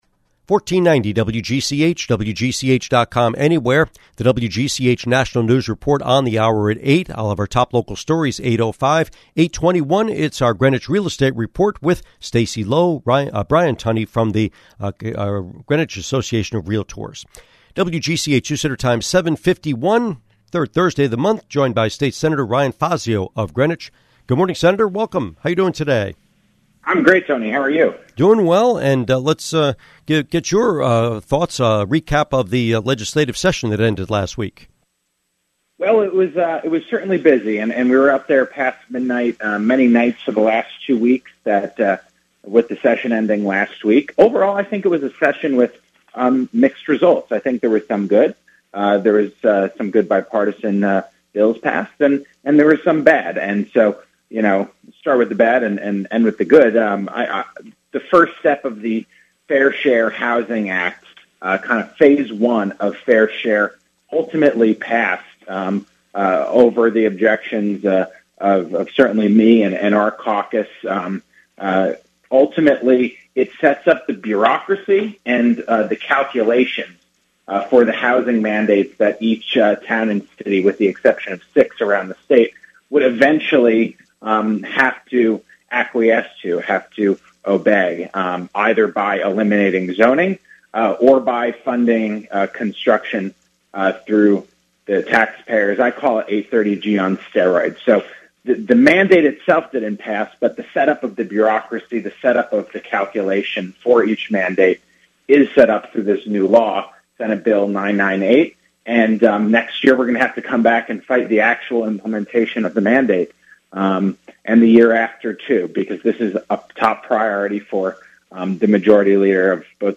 Interview with State Senator Fazio